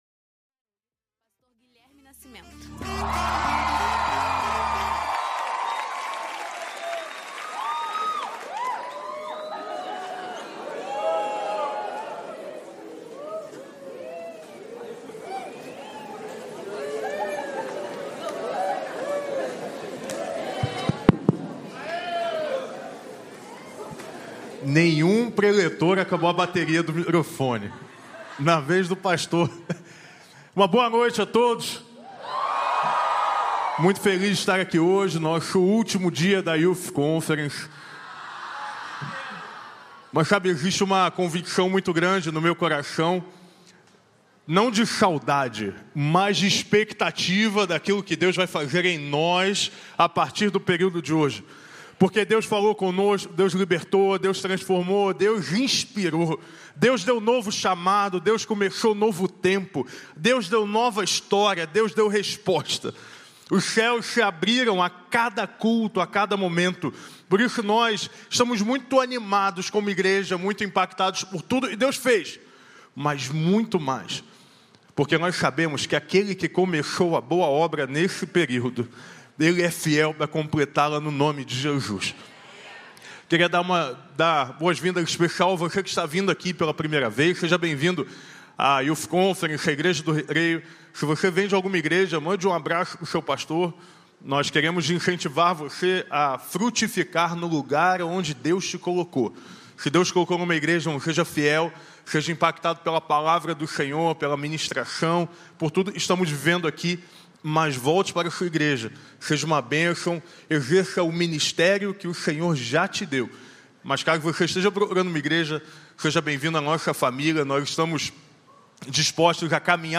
Mensagem
como parte da série YTH CON 25 na Igreja Batista do Recreio.